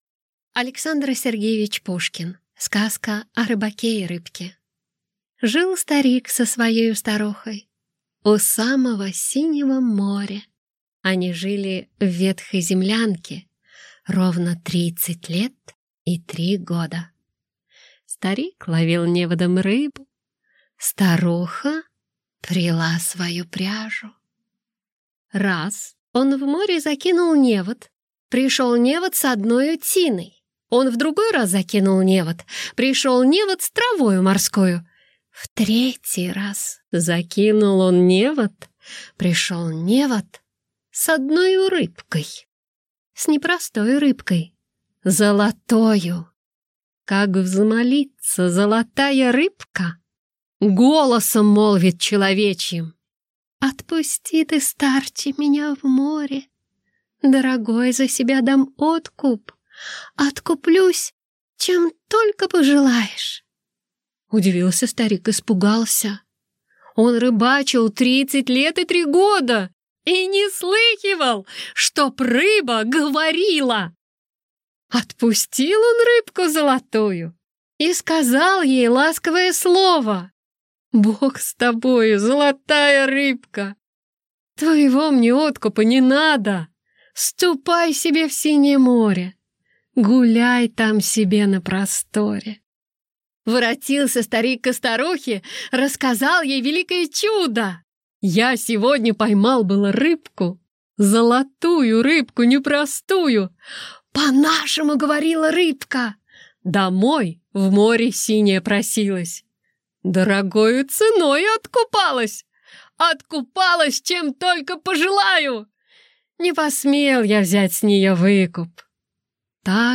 Аудиокнига Сказка о рыбаке и рыбке | Библиотека аудиокниг